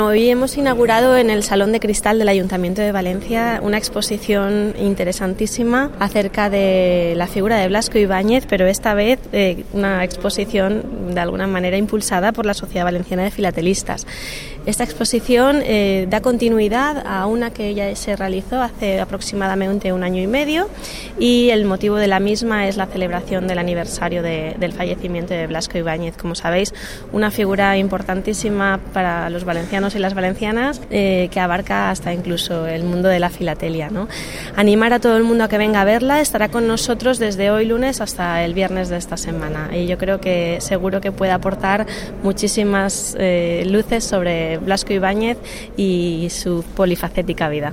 • La concejala Gloria Tello, en la inauguración de la exposición filatélica sobre Blasco Ibáñez.